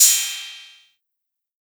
Southside Open Hatz (5).wav